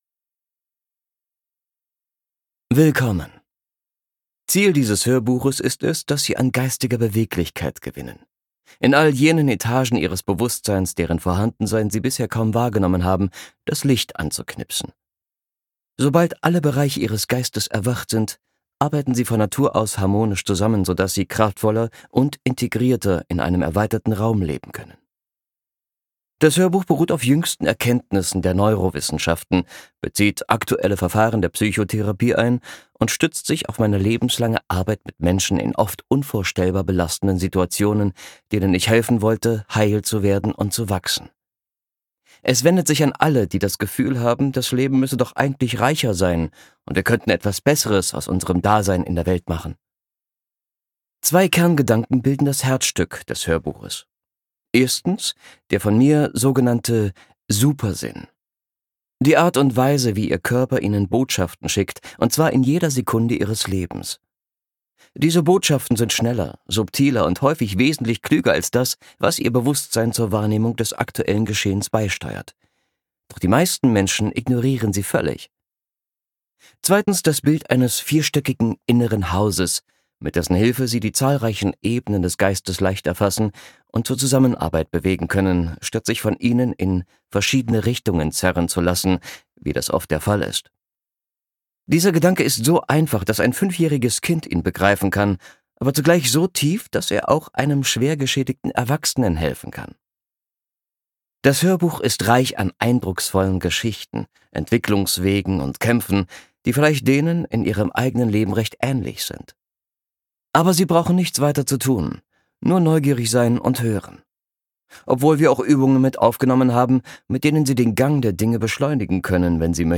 Lebendig leben! - Steve Biddulph | argon hörbuch
Gekürzt Autorisierte, d.h. von Autor:innen und / oder Verlagen freigegebene, bearbeitete Fassung.